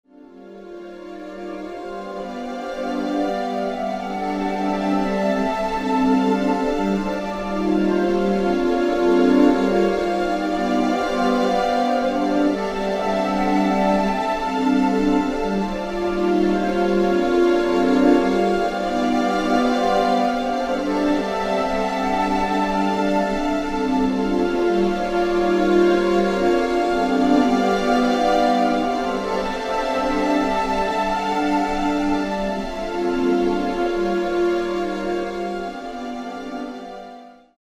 experiments with white / pink noise and static,
arrangements, both minimal and complex.